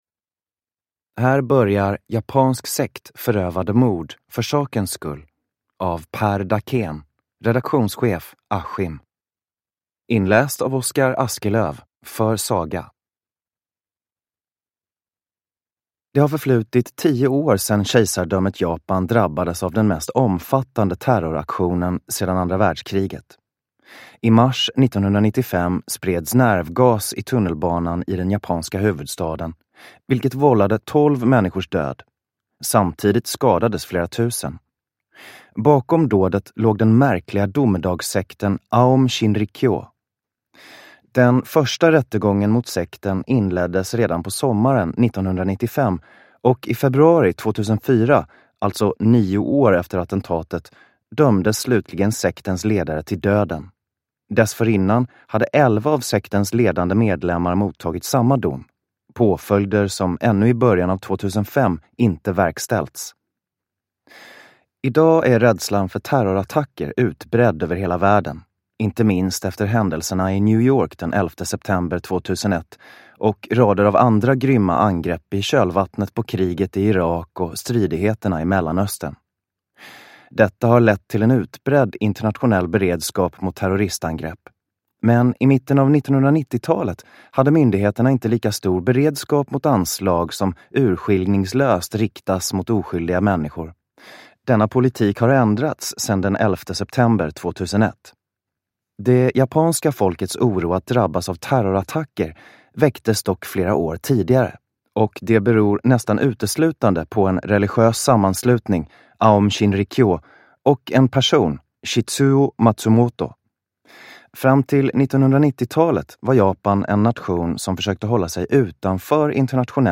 Fyra ökända sekter – Ljudbok